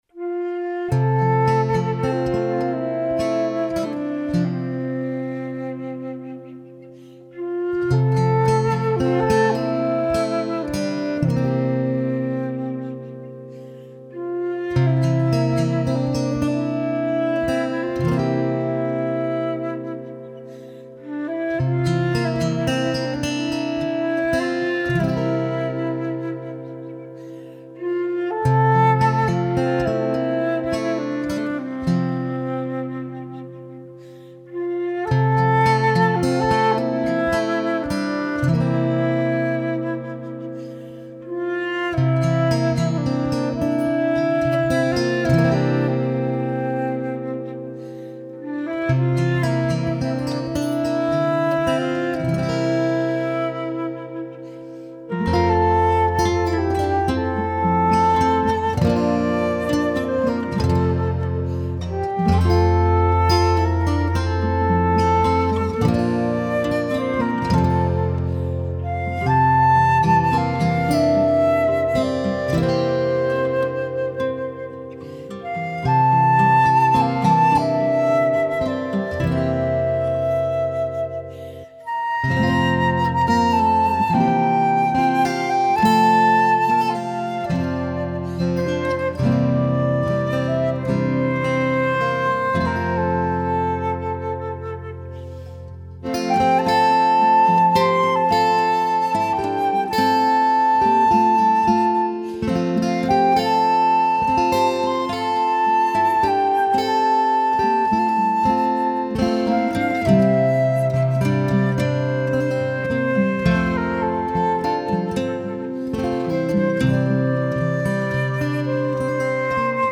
موسیقی بی کلام آرامش بخش عصر جدید گیتار